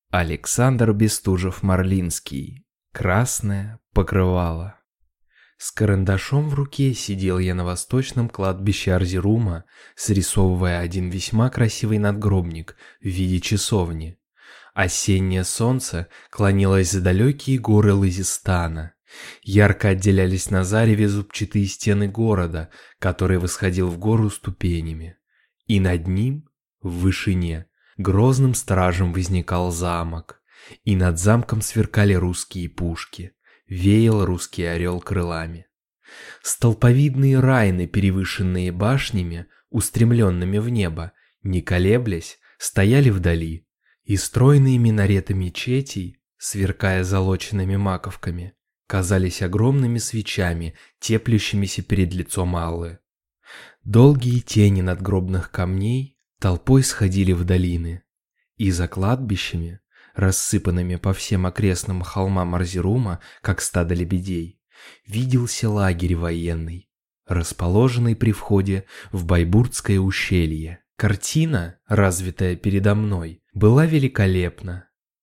Аудиокнига Красное покрывало | Библиотека аудиокниг